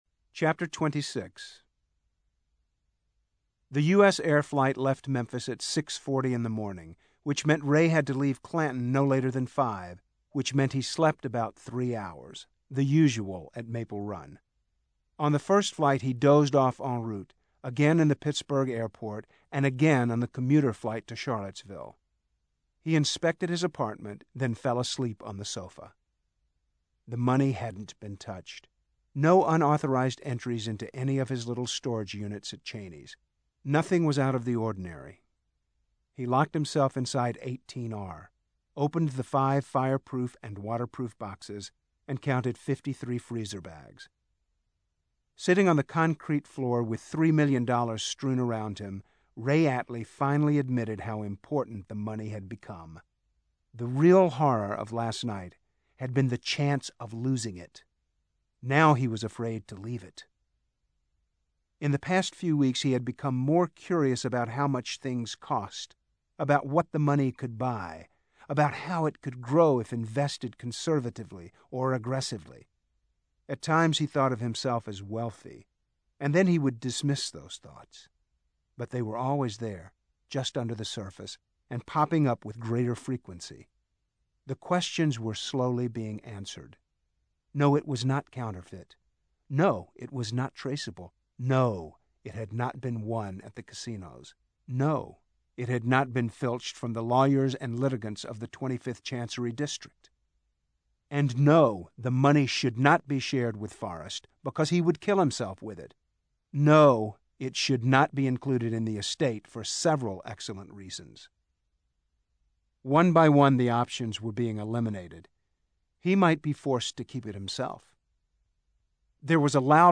ServeToMe: / 8TB-Media2 / Audio Book / John Grisham - The Summons / D06